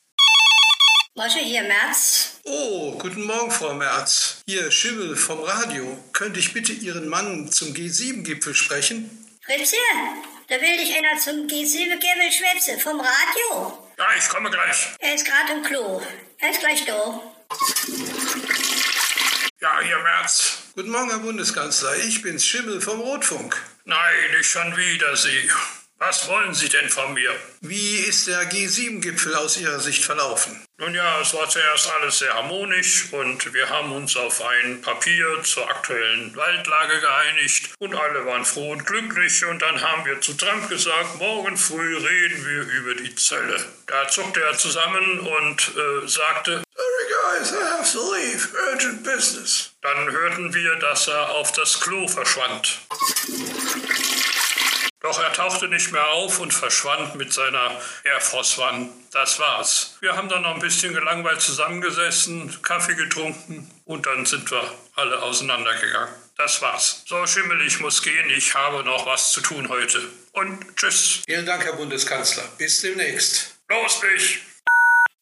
Merz Interview- G7- Gipfel